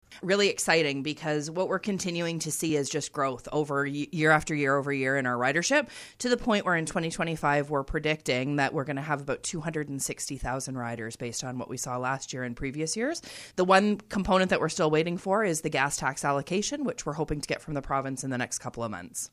The Free Orangeville transit pilot project has been a huge success.  Orangeville Mayor Lisa Post explains the increase in ridership year over year: